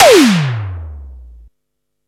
SIMMONS SDS7 10.wav